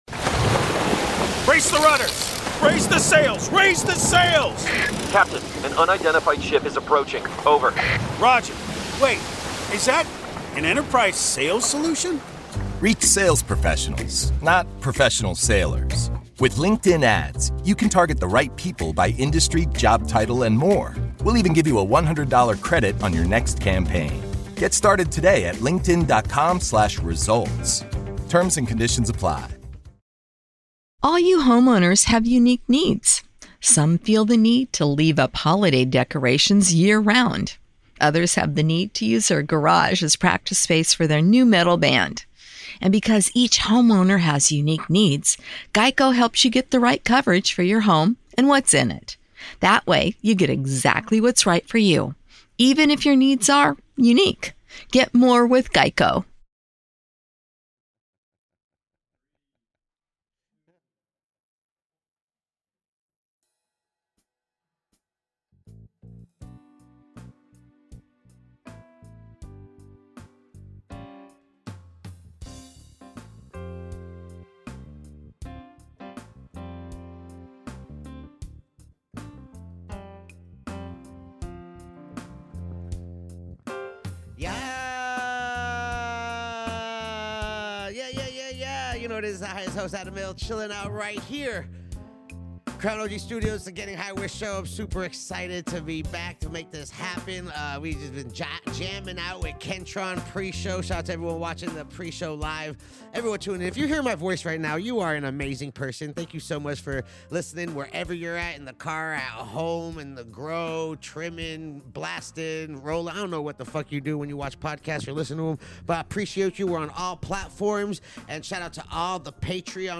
Conversing with him was akin to chatting with a whimsical uncle, filled with endless laughter and tales of adventures over the years.